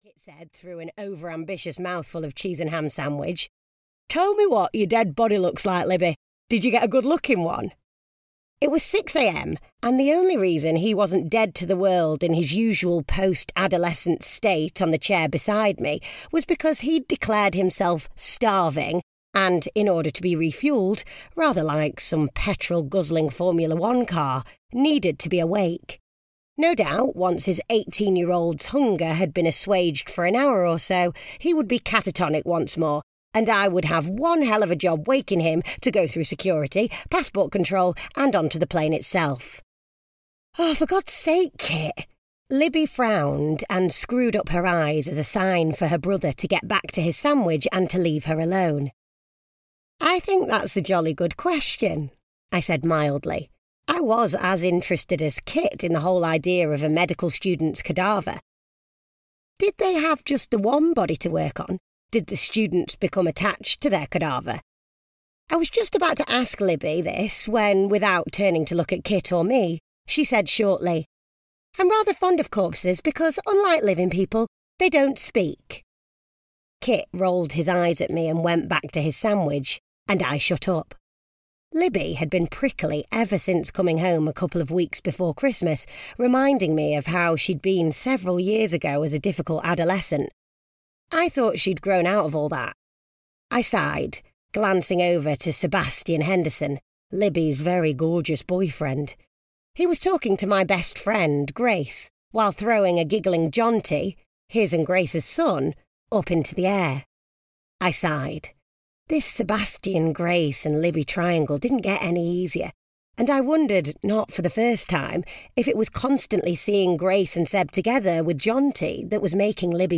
An Off–Piste Christmas (EN) audiokniha
Ukázka z knihy